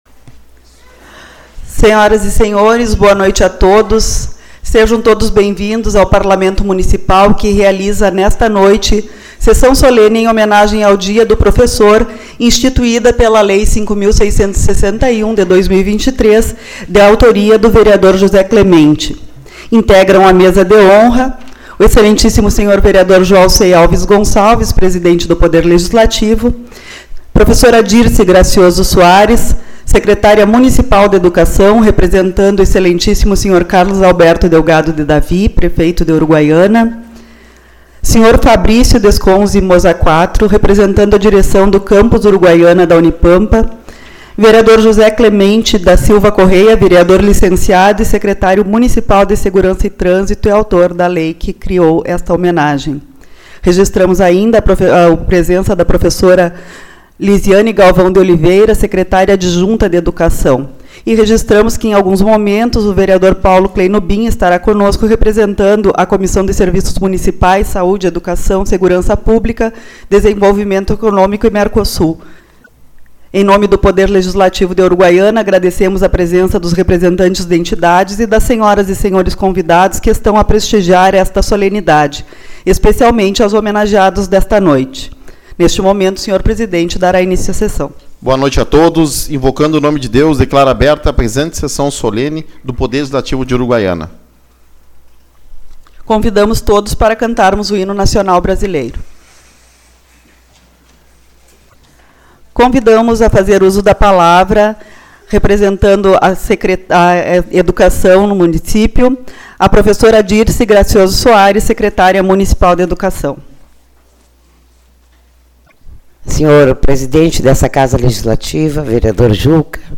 14/10 - Sessão Solene-Dia do Professor